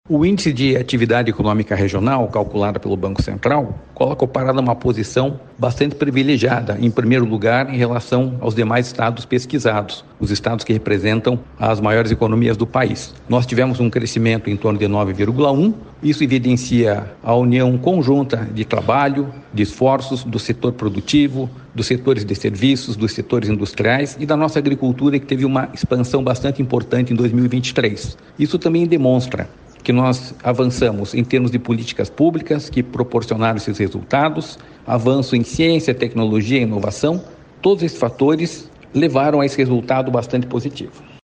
Sonora do diretor-presidente do Ipardes, Jorge Callado, sobre o Paraná ter sido o estado que mais cresceu em 2023, segundo o Banco Central